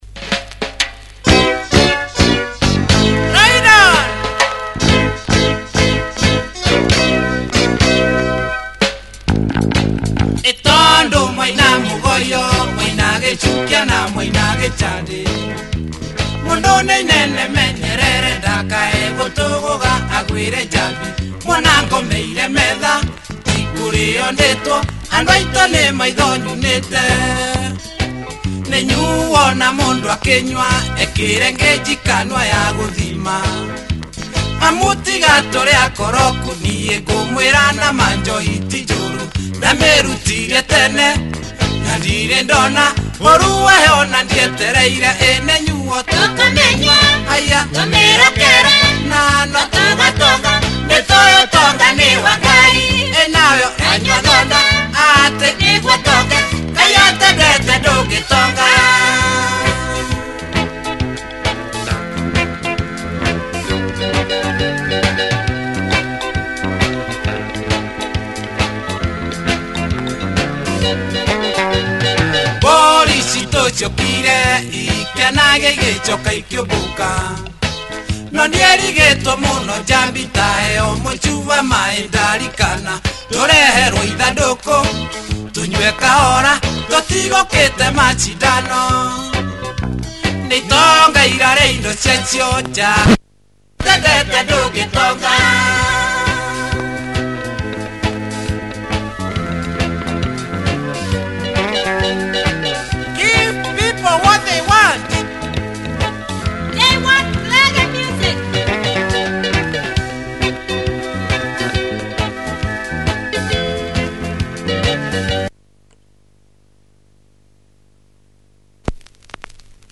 A slight reggae flavored synth thing
Kikuyu Benga in a more pop fashion